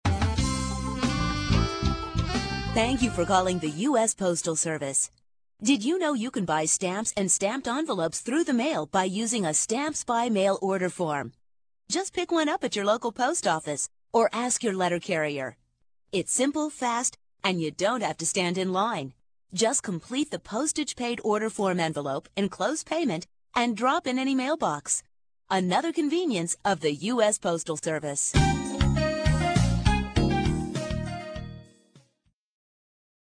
Audio Demos - Message On Hold